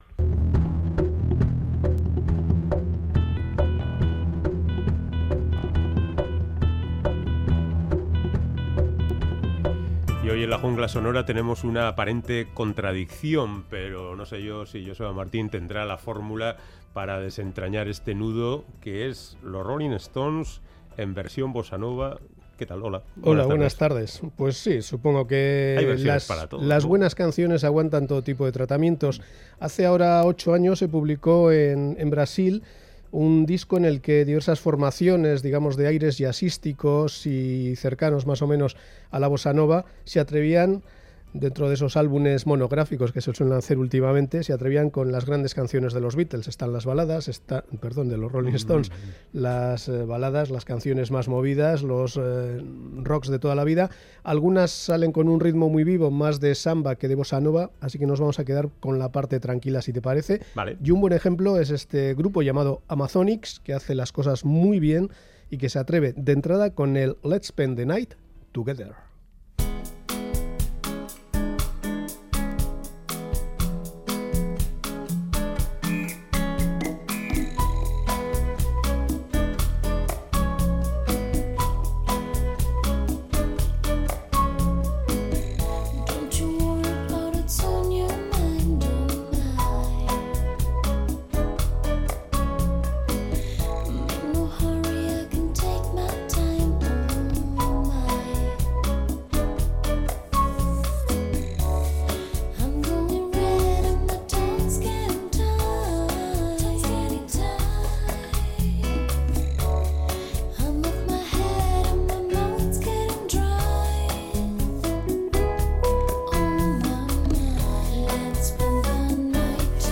tranquilos ritmos brasleños